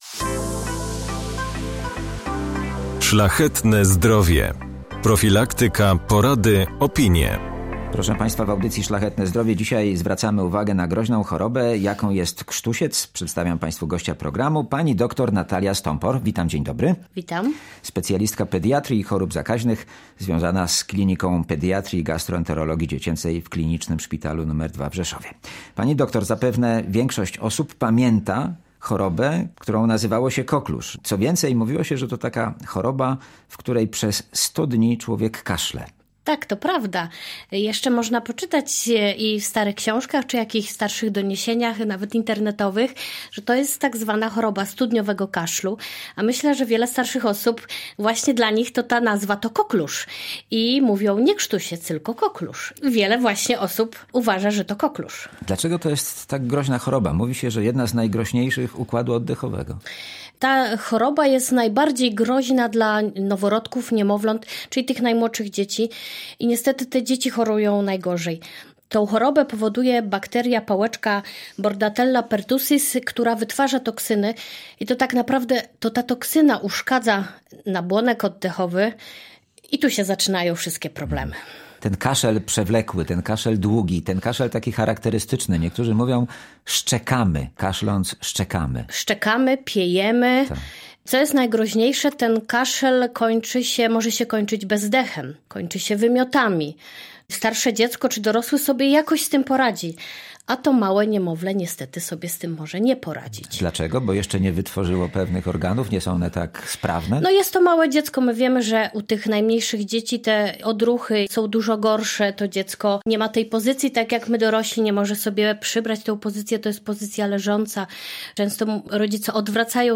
W audycji Szlachetne Zdrowie rozmowa o groźnej chorobie, która jest szczególnie niebezpieczna dla noworodków i małych dzieci. Krztusiec – to zakaźna choroba dróg oddechowych, ostatnio występuje tak często, że lekarze mówią o epidemii tej choroby.